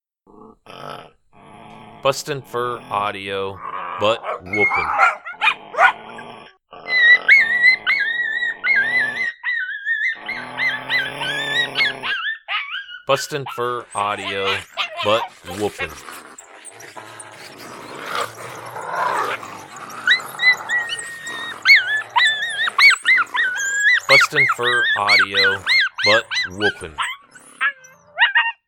Adult Coyote and Juvenile Coyote food fight.